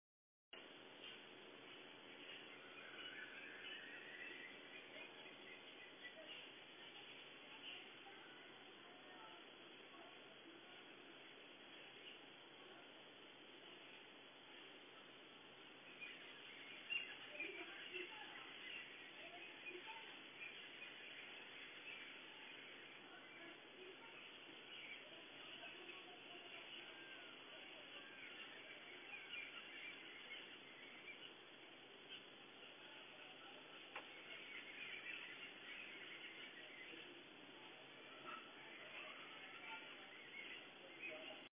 Download Jungle sound effect for free.
Jungle